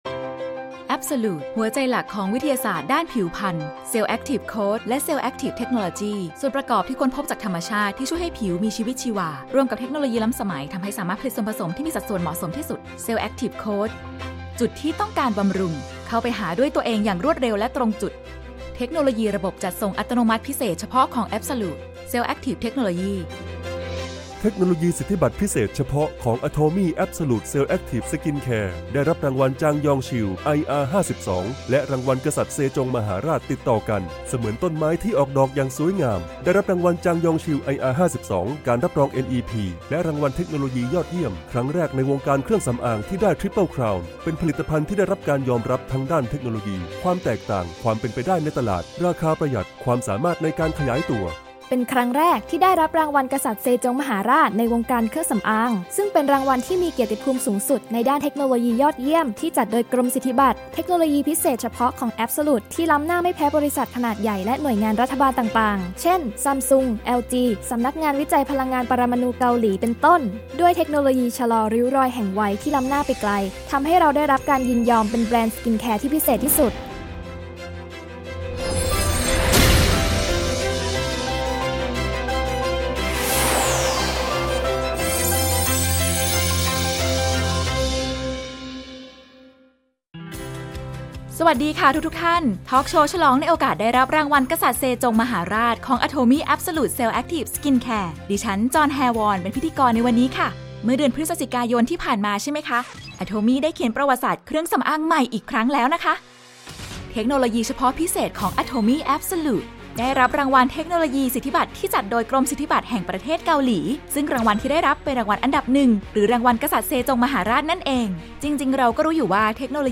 Atomy Masstige - แอบโซลูท เซลแอคทีฟ สกินแคร์ ✨ TALK SHOW